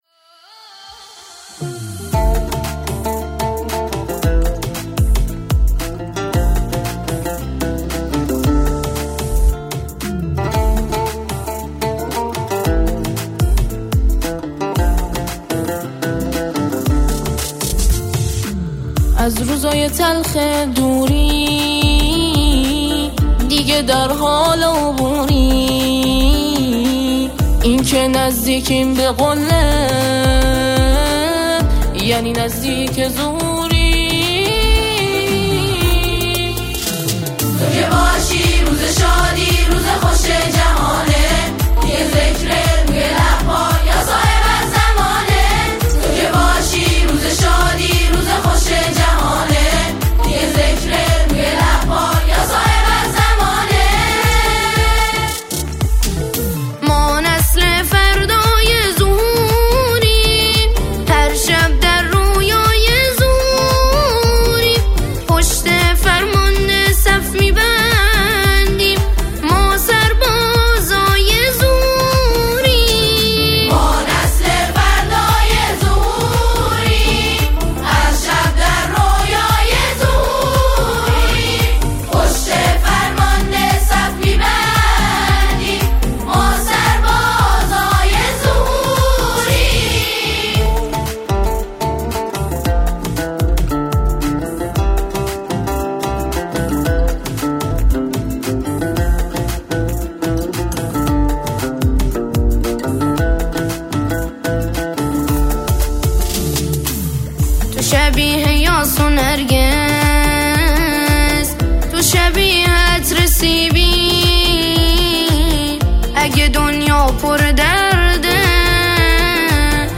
سرودهای امام زمان (عج)
با صدای پسران